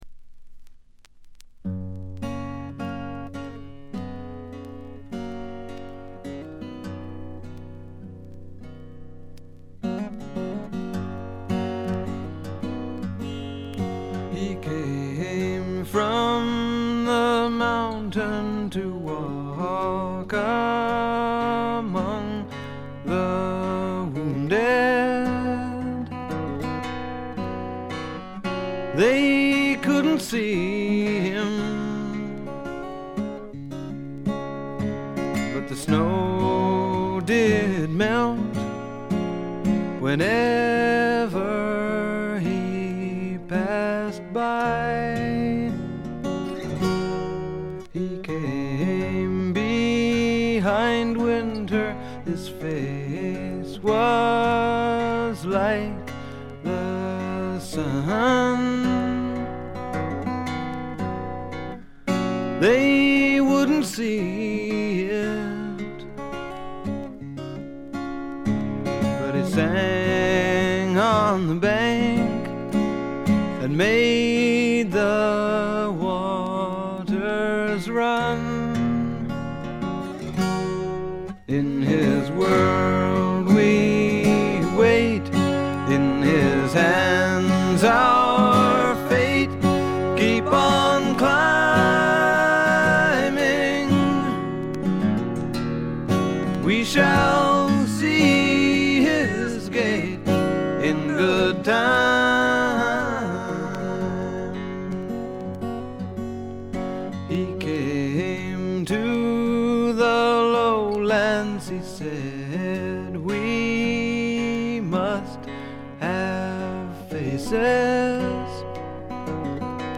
散発的なプツ音少し。
試聴曲は現品からの取り込み音源です。